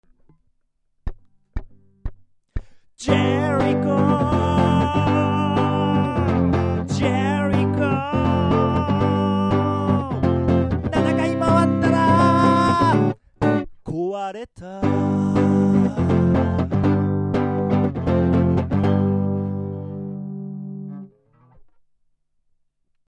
実験的讃美歌Project - 3